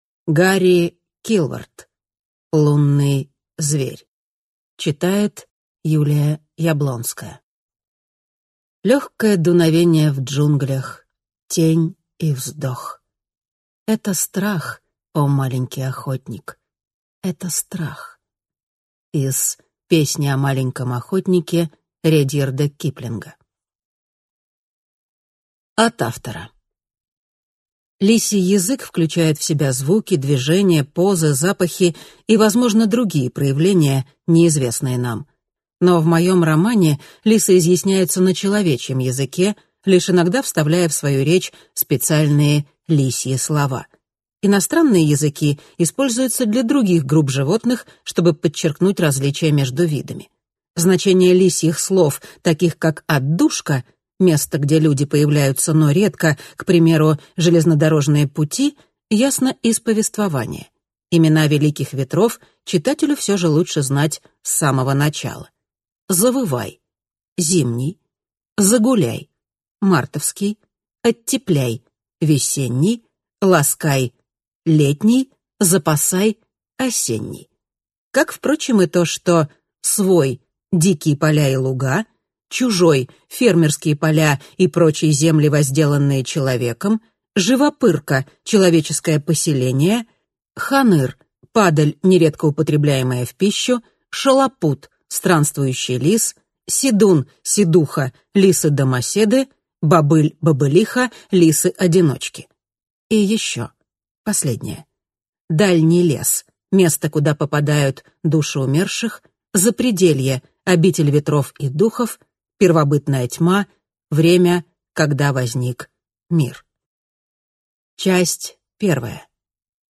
Аудиокнига Лунный зверь | Библиотека аудиокниг